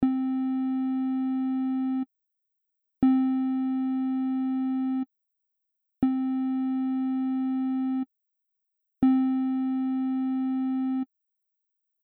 ADSR-Hüllkurve mit D kurz und D lang / ASR konstant (Operator-Einstellungen: A 0,00ms - D 300ms & 1,25s - R 50ms - S -10db)
ADSR_-_kurz_D_lang_D.mp3